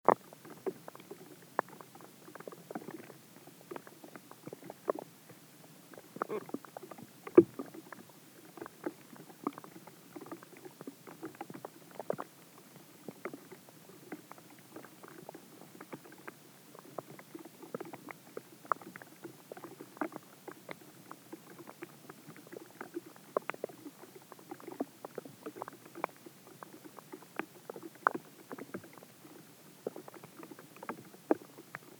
Beim Neue-Musik-Festival "Der Sommer in Stuttgart" im Wald aufgenommene Klänge, zum Anhören bitte anklicken:
Tierlaute vom Hydrofon im Teich,
HydrofonTeichTierlaut.mp3